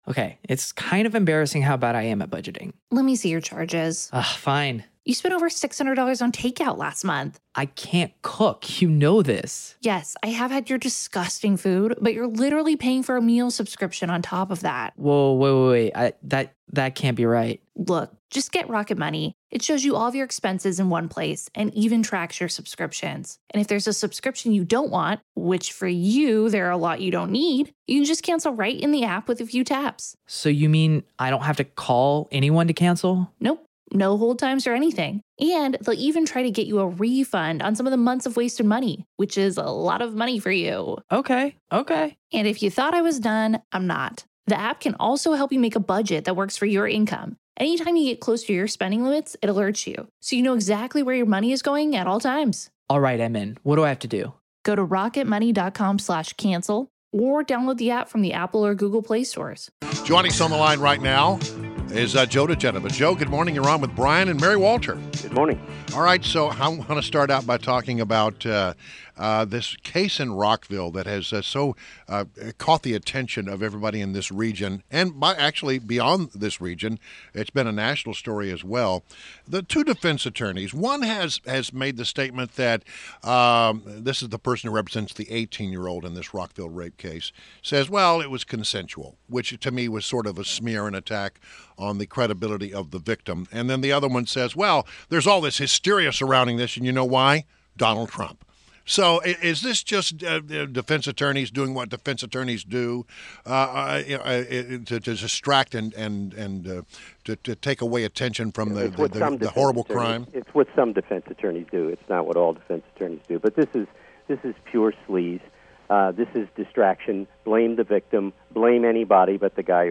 WMAL Interview - JOE DIGENOVA - 03.27.17